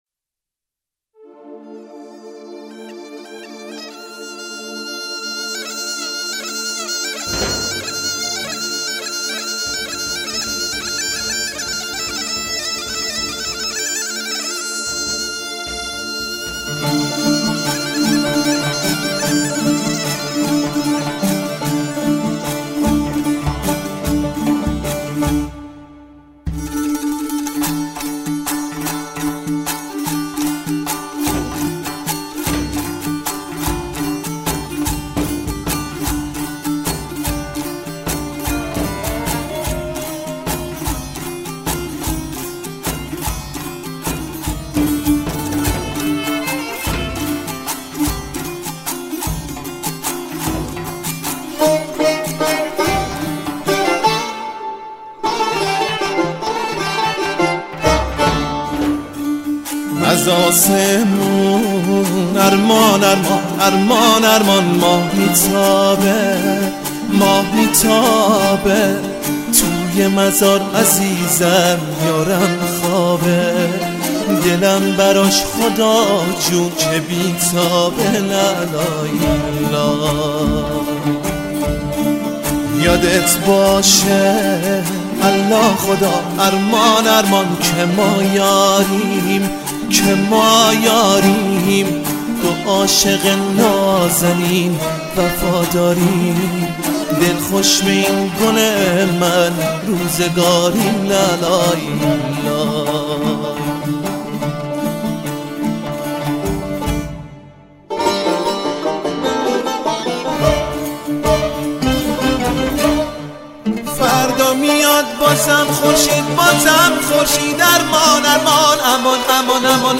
اهنگ محلی